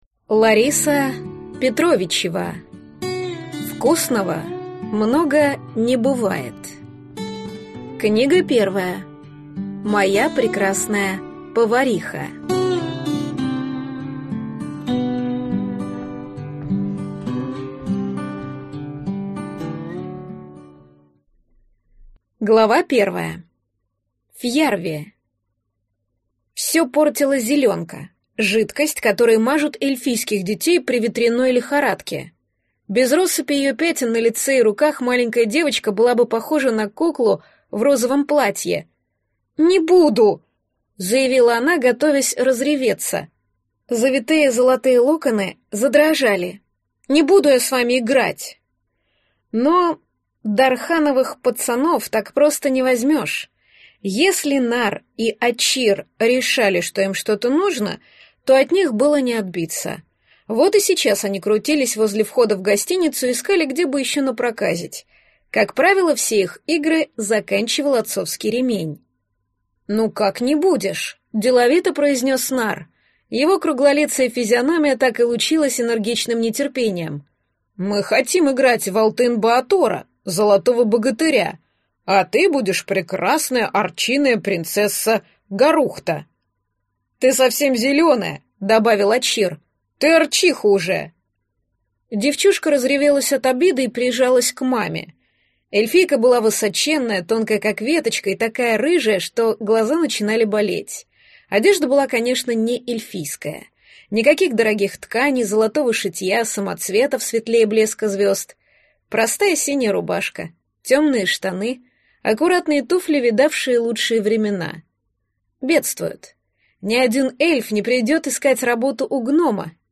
Аудиокнига Моя прекрасная повариха. Книга 1 | Библиотека аудиокниг